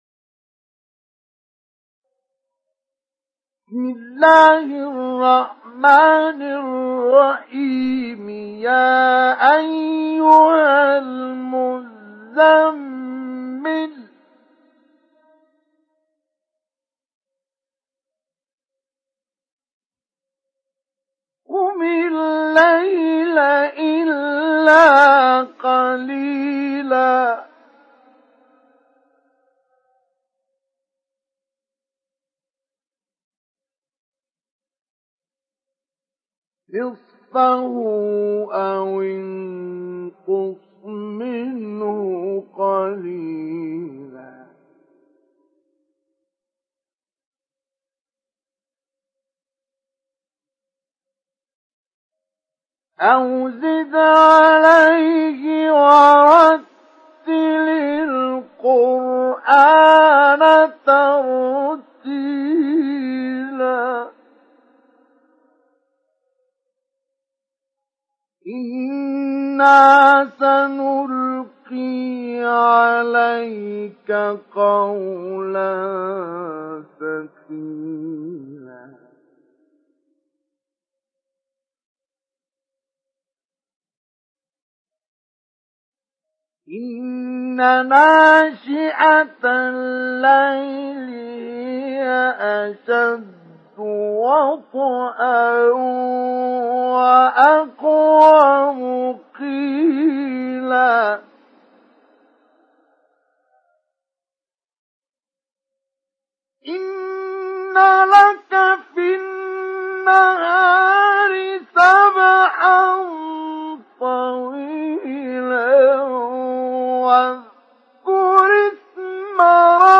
تحميل سورة المزمل mp3 بصوت مصطفى إسماعيل مجود برواية حفص عن عاصم, تحميل استماع القرآن الكريم على الجوال mp3 كاملا بروابط مباشرة وسريعة
تحميل سورة المزمل مصطفى إسماعيل مجود